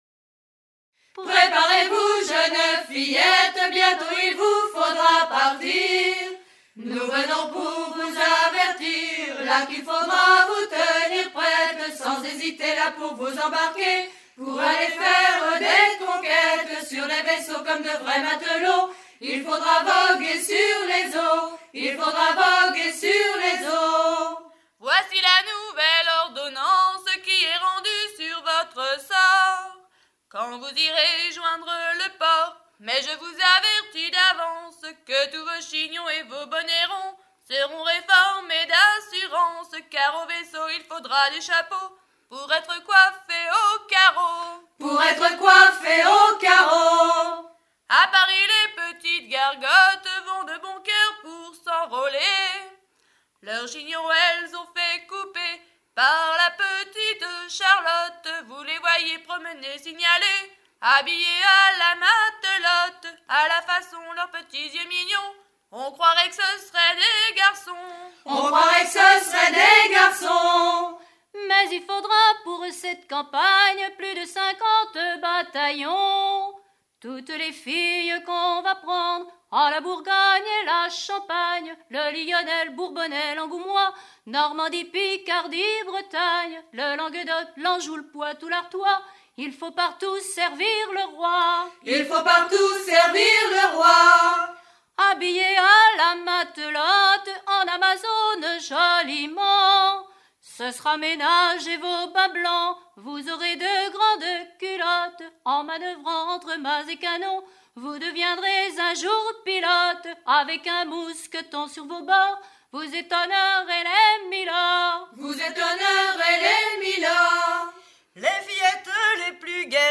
Mélodie et quatre couplets recueillis en 1980
Pièce musicale éditée